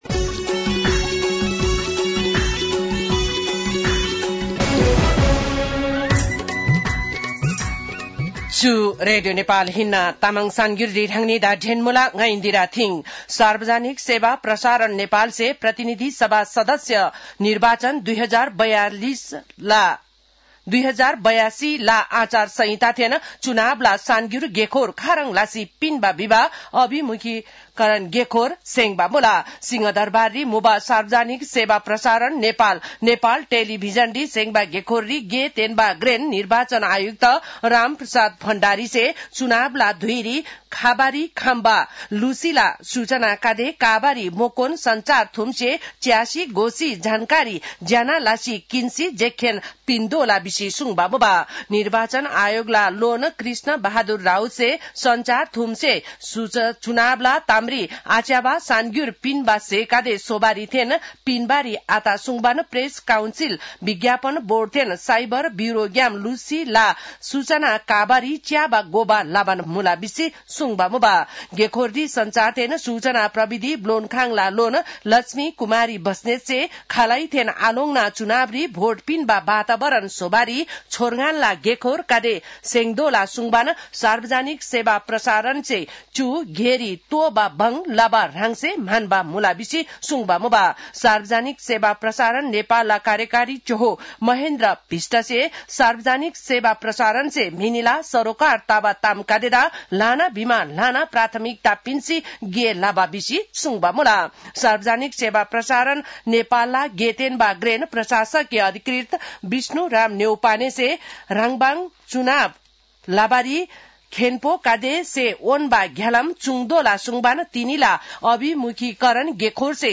तामाङ भाषाको समाचार : १२ माघ , २०८२